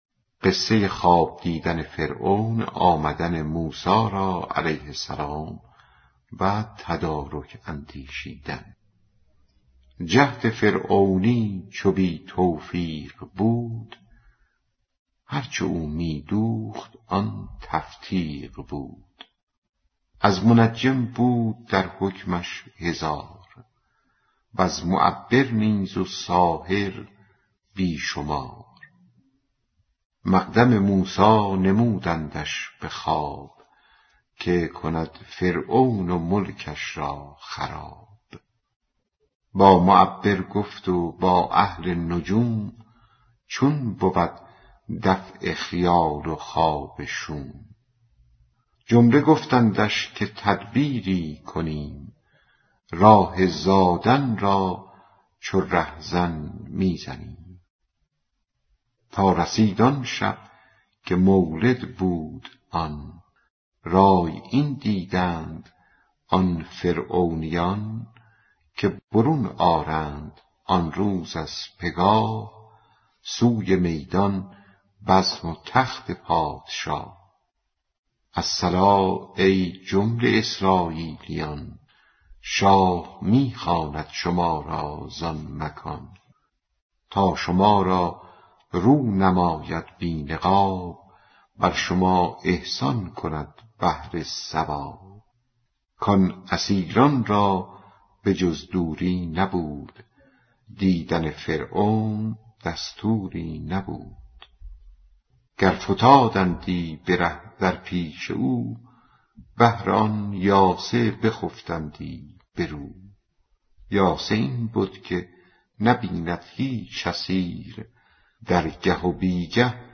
دکلمه در خواب دیدن فرعون به دنیا آمدن موسی را